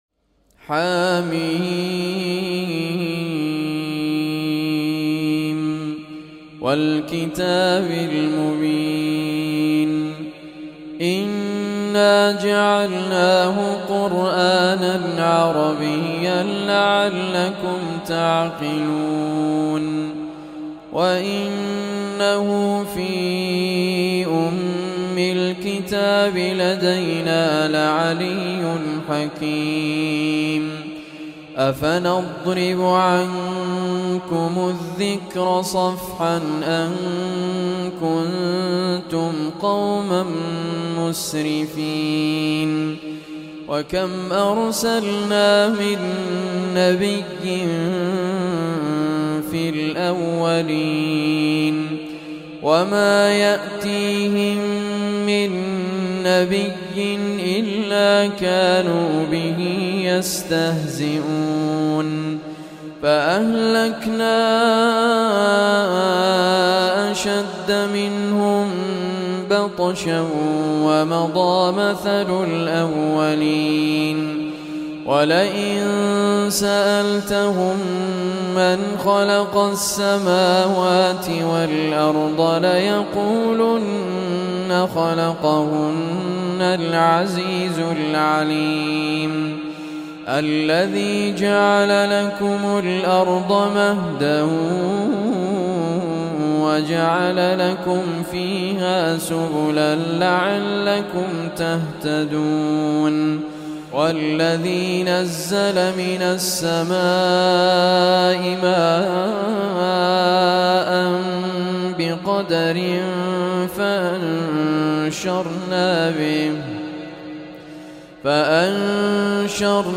Surah Az Zukhruf Recitation